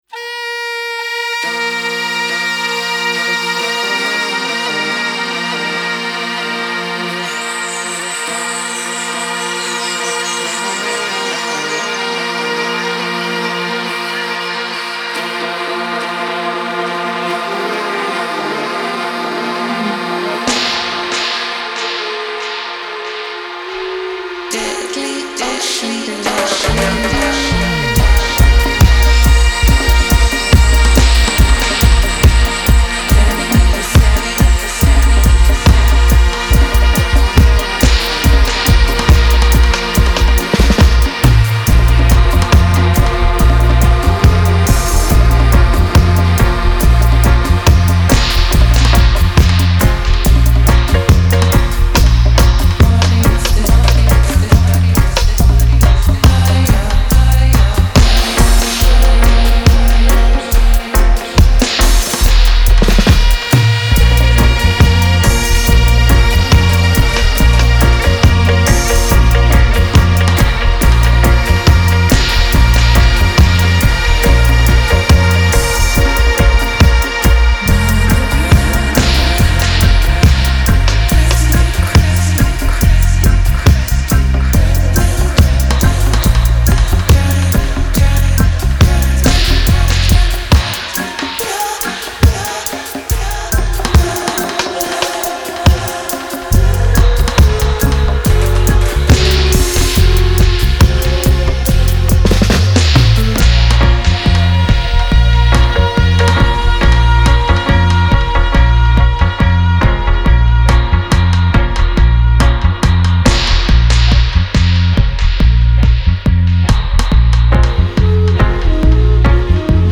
dub version